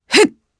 Demia-Vox_Landing_jp.wav